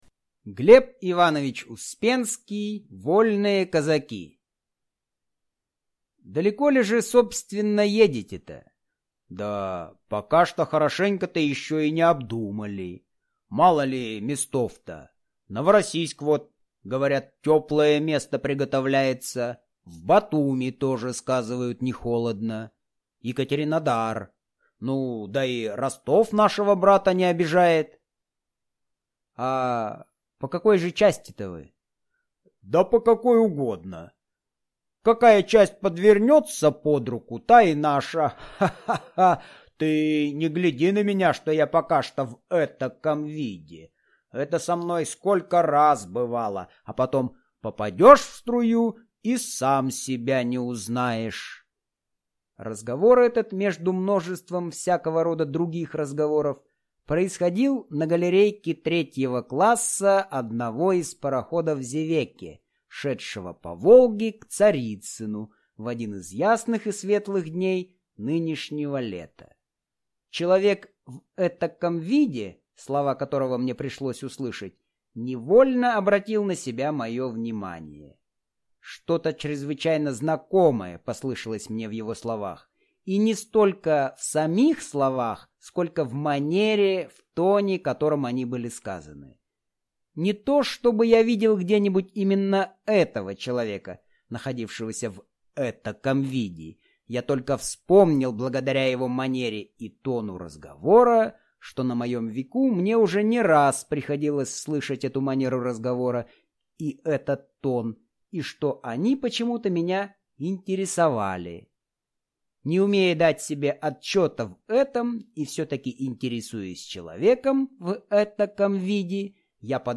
Аудиокнига Вольные казаки | Библиотека аудиокниг